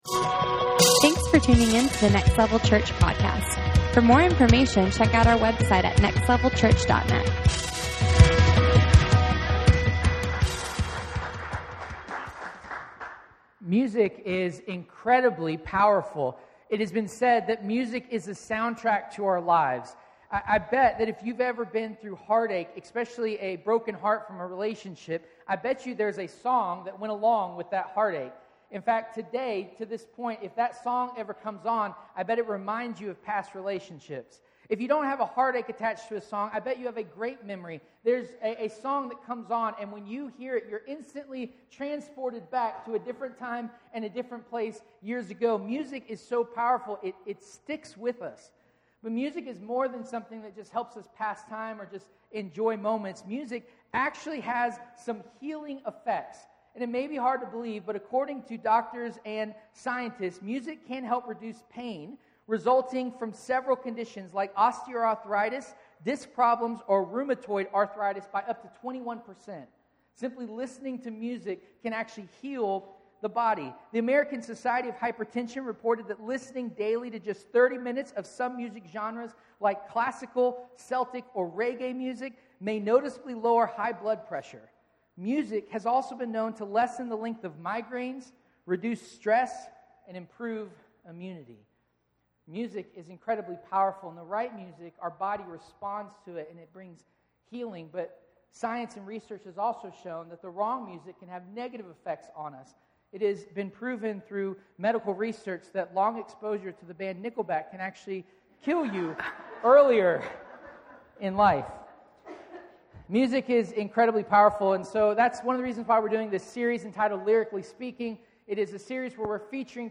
Lyrically Speaking Service Type: Sunday Morning « Lyrically Speaking